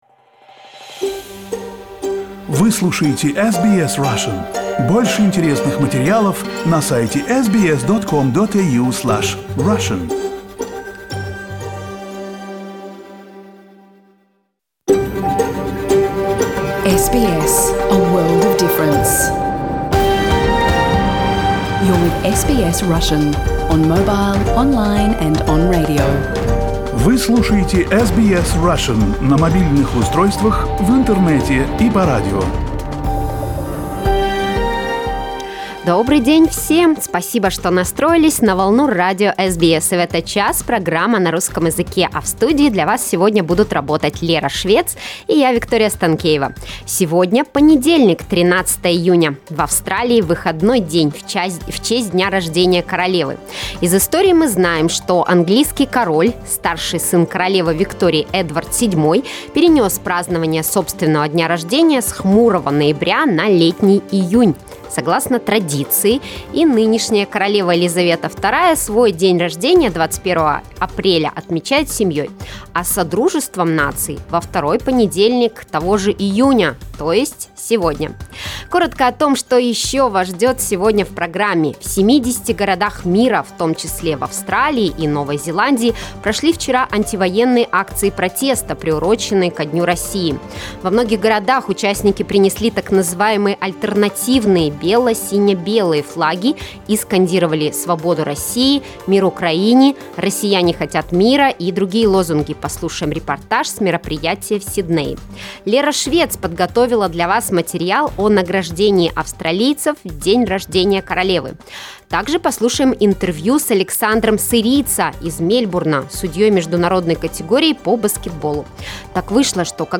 You can listen to SBS Russian program live on radio, on our website and in the SBS Radio app.